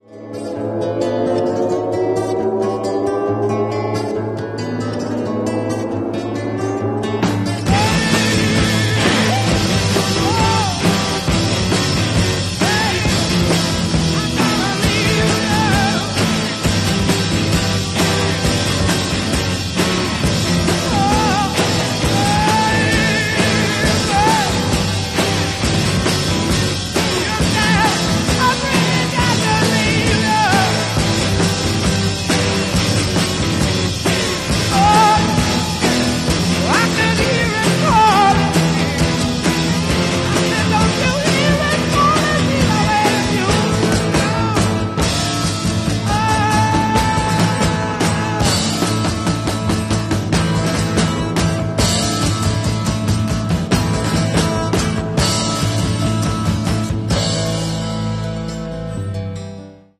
(both lipsynched)